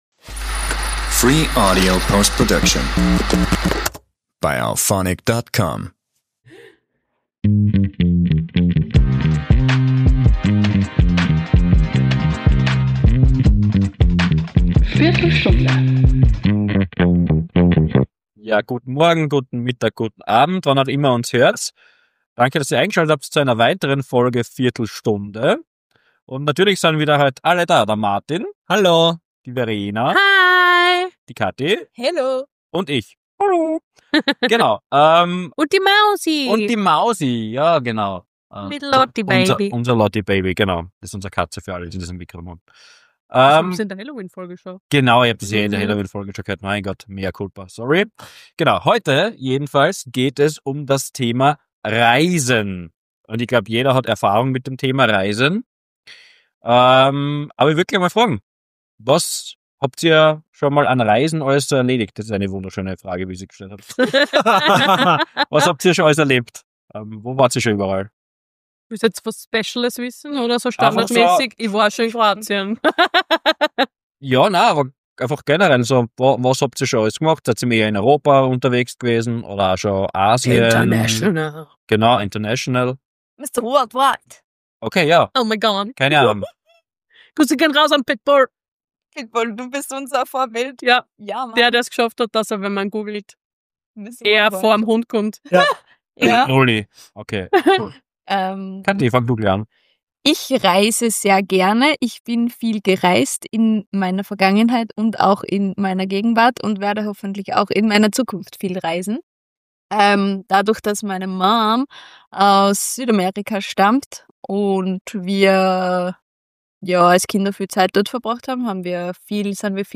Wir quatschen heute über alles, was mit Urlaub zu tun hat! Zu viert diskutieren wir über unsere Bucket Lists, vergangene Trips und unsere ganz persönlichen Travel-Styles. Spoiler: Wir sind uns nicht immer einig, aber wir haben extrem viel gelacht.